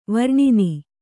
♪ varṇini